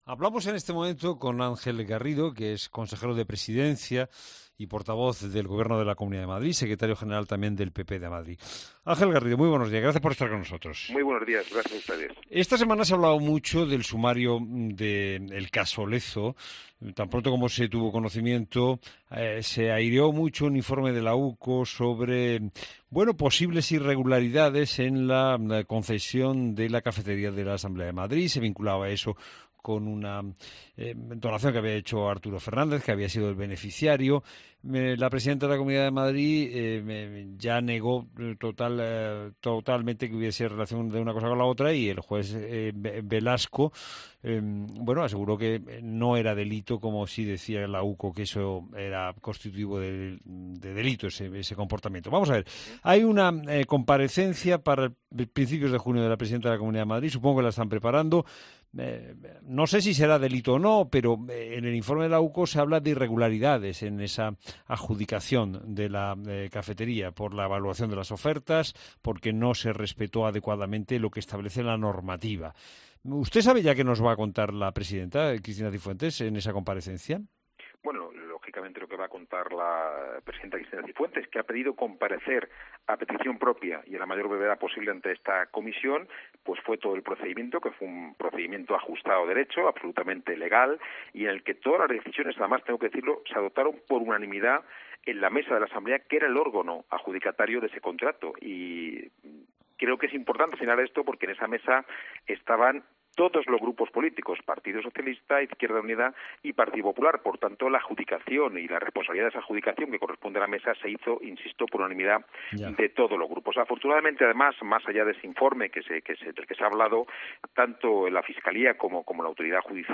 Ángel Garrido, consejero de Presidencia y Portavoz de la Comunidad de Madrid, en "La Mañana Fin de Semana"